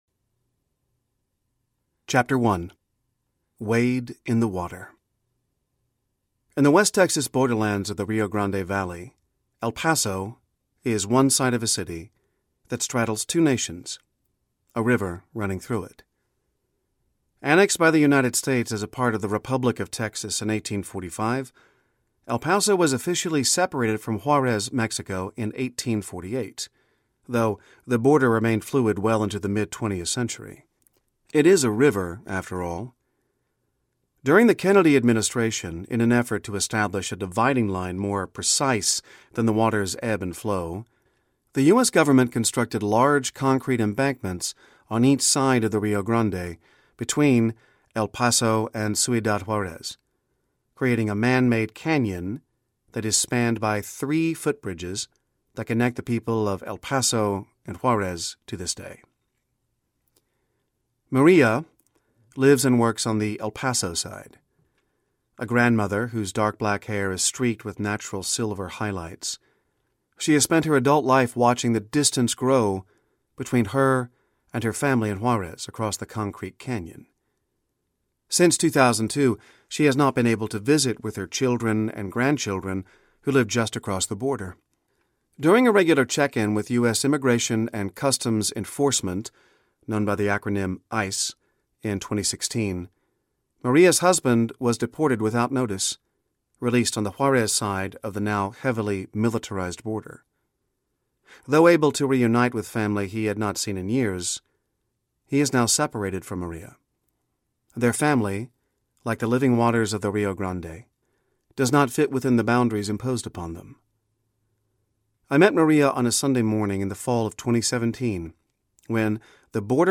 Revolution of Values Audiobook
Narrator